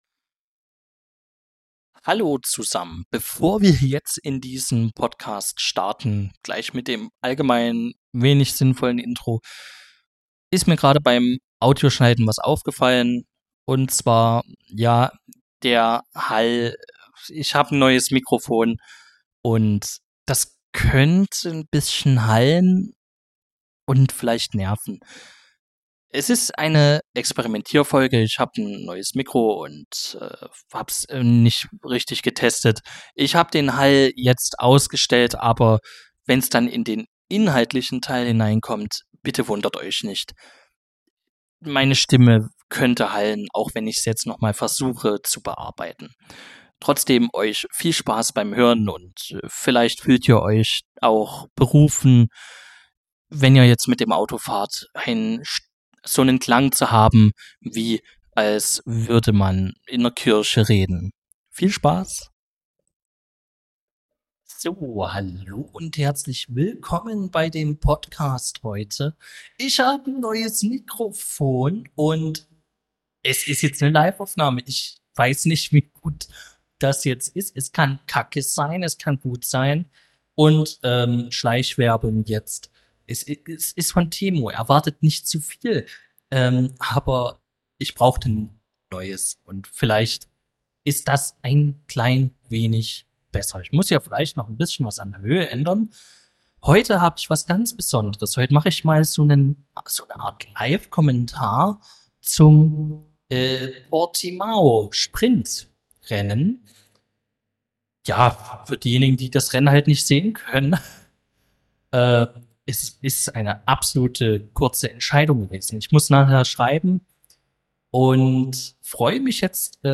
Dabei wird das neue Mikro getestet und es passieren Live-Sachen. Der Hall blieb zum Glück zurück.